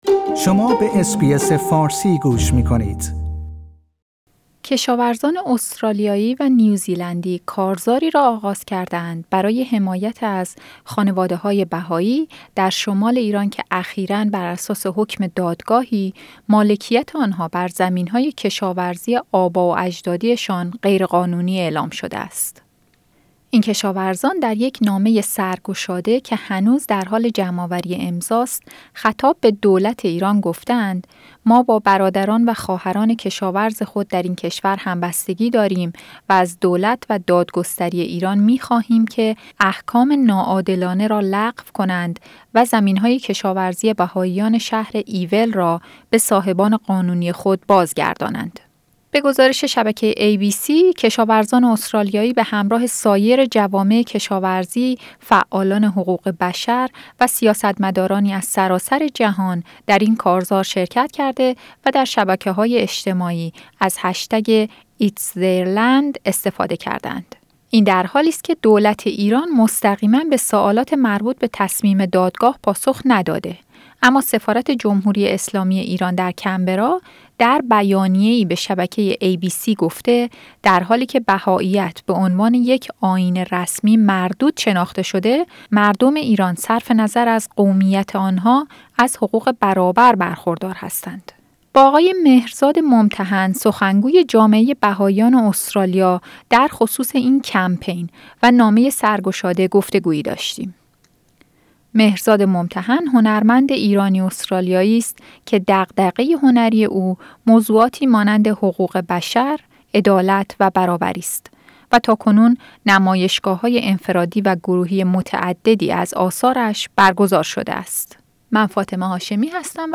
گفتگویی داشتیم